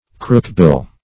Crookbill \Crook"bill`\ (kr[oo^]k"b[i^]l`), n.